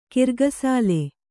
♪ kirgasāle